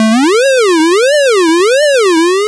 retro_synth_wobble_01.wav